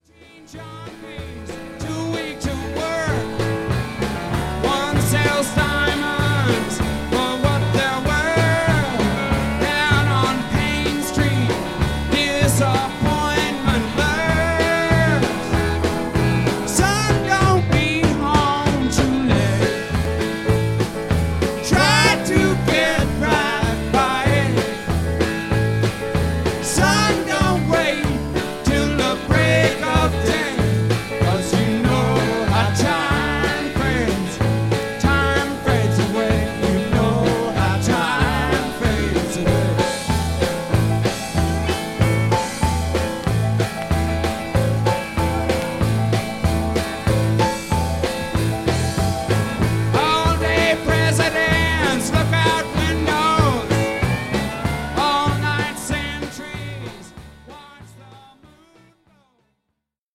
全曲新曲で構成され、初のライブ・アルバム。